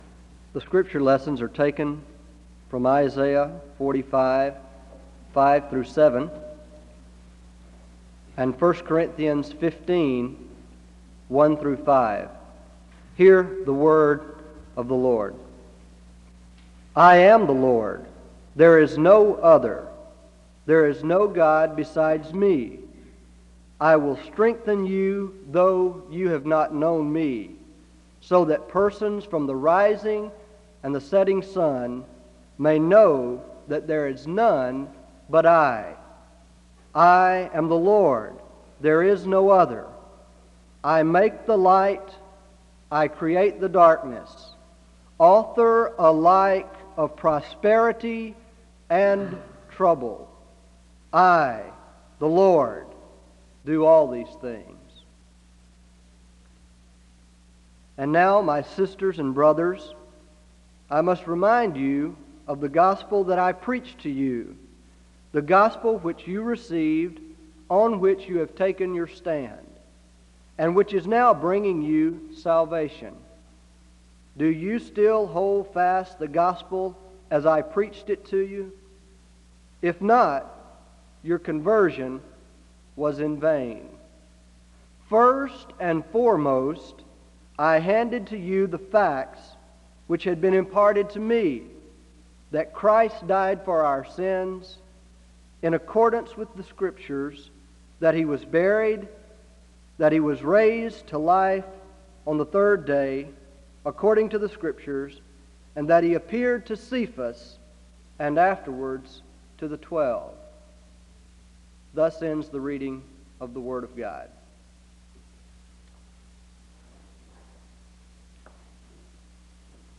Isaiah 45:5-7 and 1 Corinthians 15:1-5 are read aloud (0:00-1:54). The choir leads in song (1:54-5:10).
He uses the concept of the cross and the resurrection as examples of the tension between God’s yes and no and how both are important in the gospel (7:58-24:46). Chapel is closed in prayer (24:47-25:18).